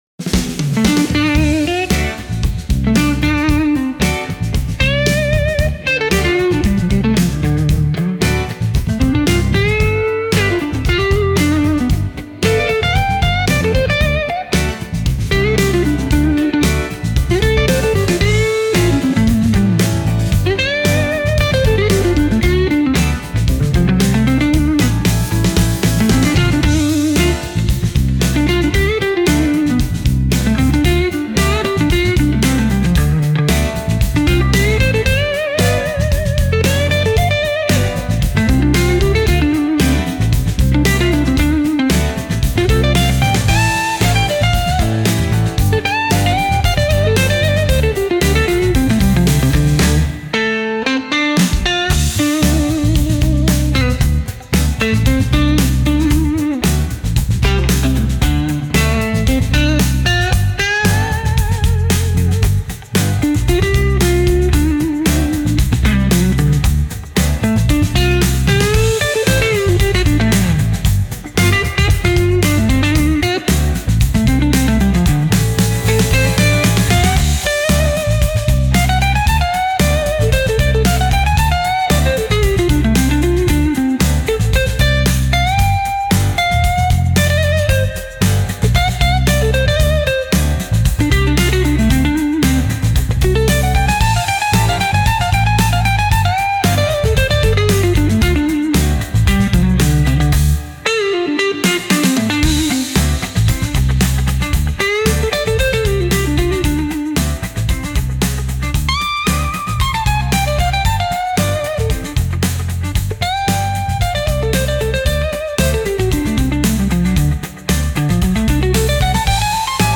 Instrumental - Real Liberty Media Dot XYZ- 4.00 mins.mp3